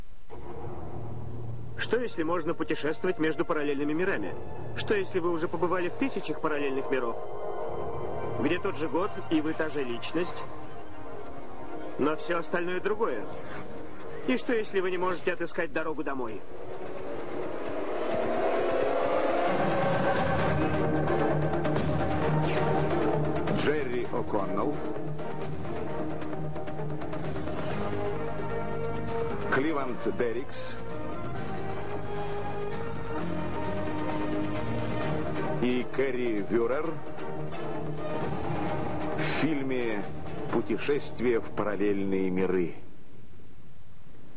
Die zweite Fassung des Intros aus der vierten Staffel wird von einem anderen russischen Sprecher gesprochen. Außerdem ist die englische Originaltonspur nicht hörbar.